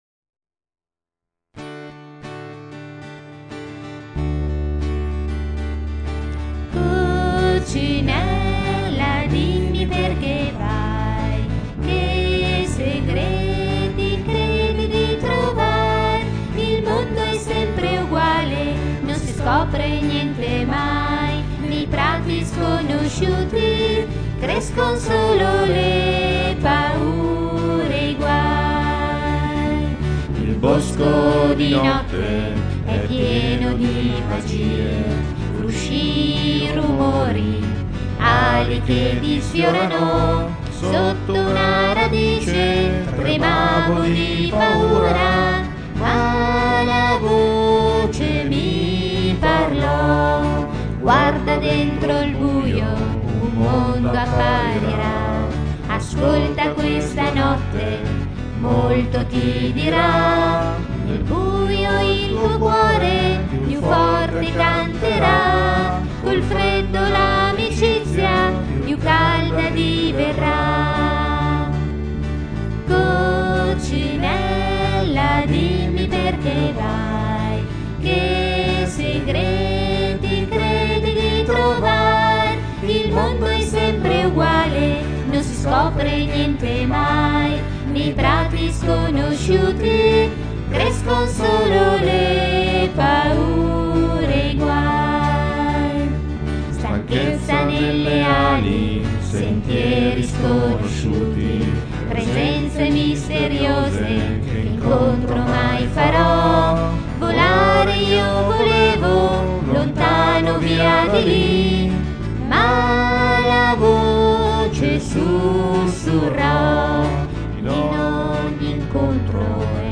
Canto del cerchio